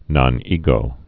(nŏn-ēgō, -ĕgō)